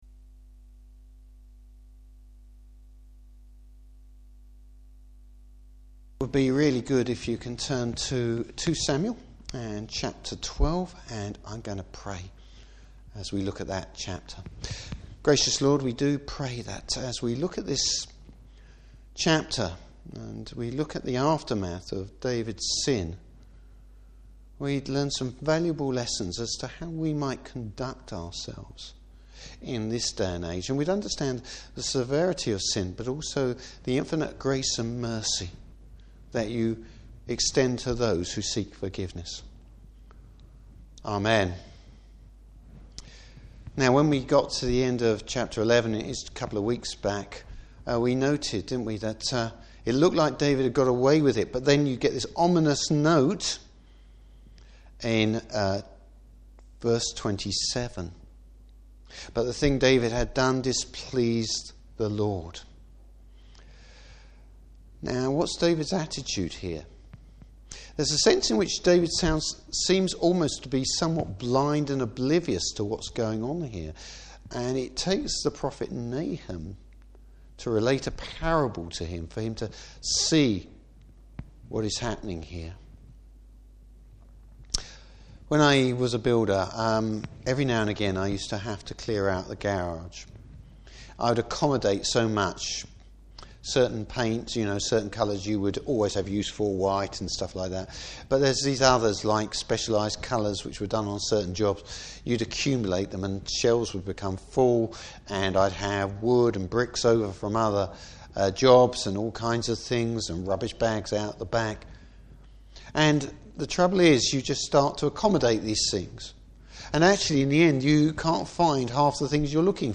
Service Type: Evening Service David is forgiven, but there are still consequences due to his sin.